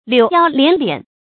柳腰蓮臉 注音： ㄌㄧㄨˇ ㄧㄠ ㄌㄧㄢˊ ㄌㄧㄢˇ 讀音讀法： 意思解釋： 腰如柳，臉似蓮。形容女性之美。亦代指美女。